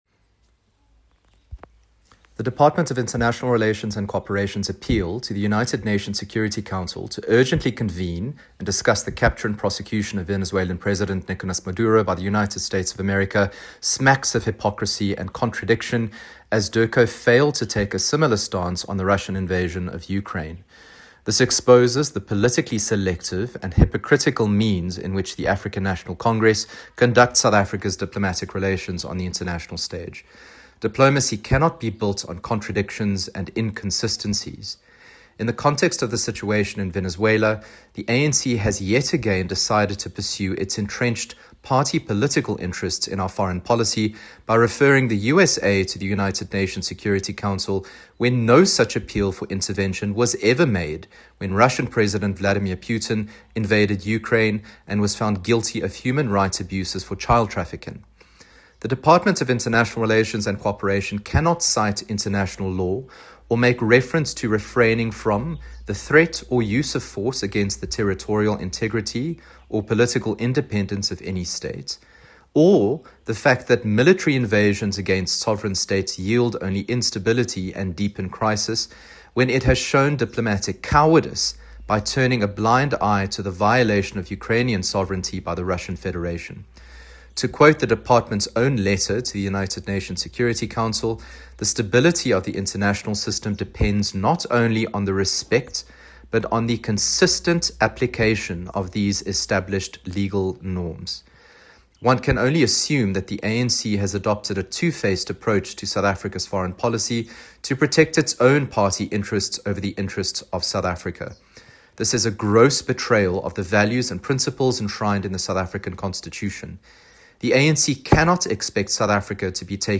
soundbite by Ryan Smith MP.